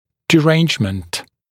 [dɪ’reɪnʤmənt][ди’рэйнджмэнт]расстройство, нарушение, дисфункция